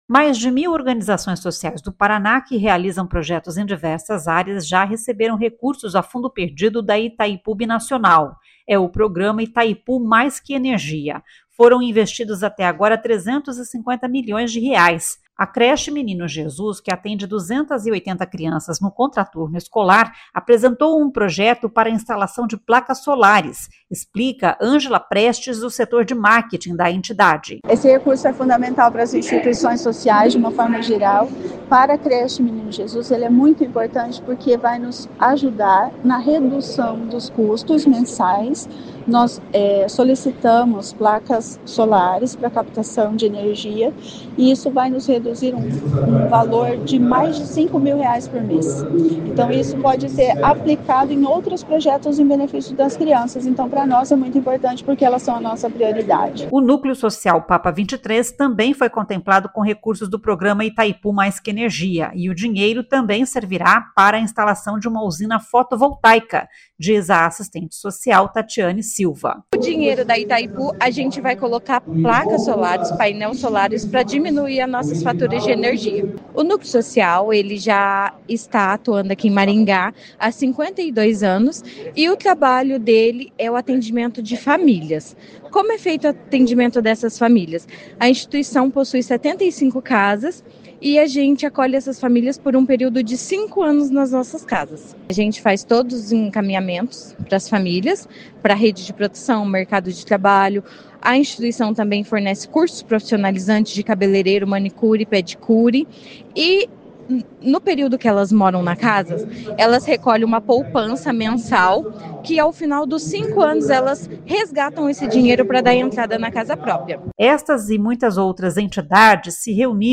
Juntas elas vão receber R$ 25 milhões, diz o diretor-geral brasileiro da Itaipu Binacional, Ênio Verri.